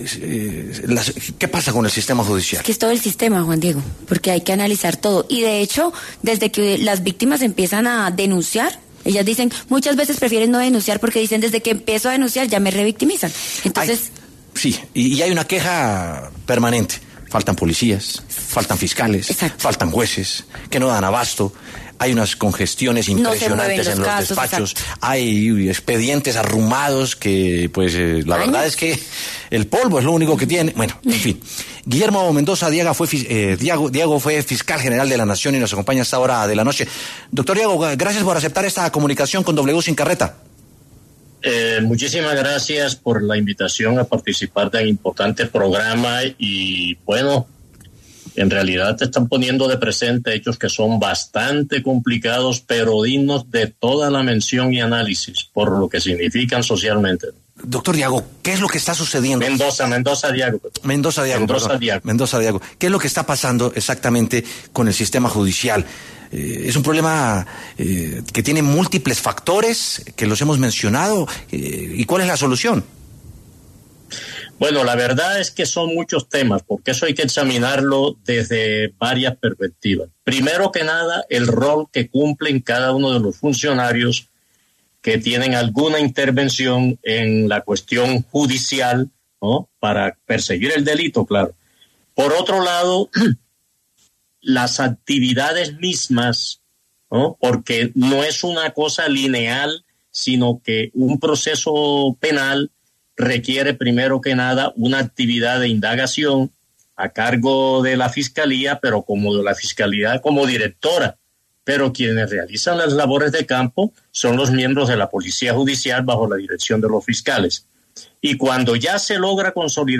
El exfiscal Guillermo Mendoza Diago pasó por los micrófonos de W Sin Carreta